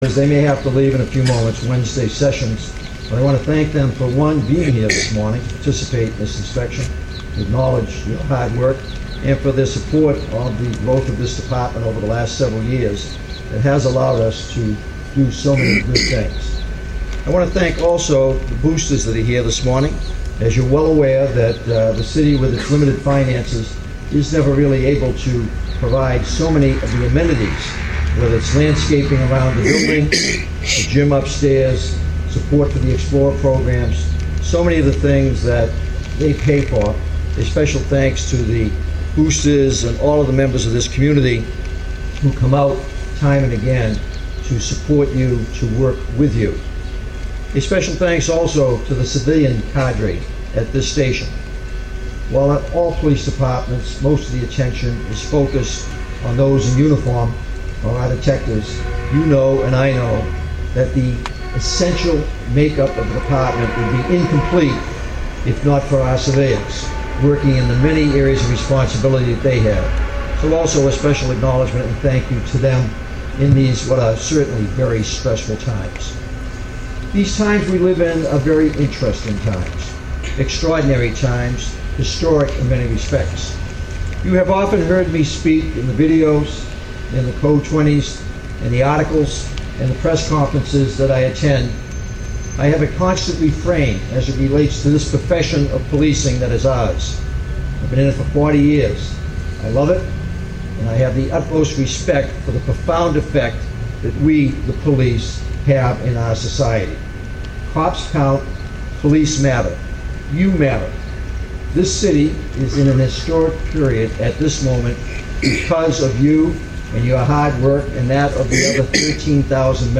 This morning, despite the gloomy weather, Foothill Division held its annual Formal Command Inspection.